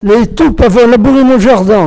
Sables-d'Olonne (Les)
locutions vernaculaires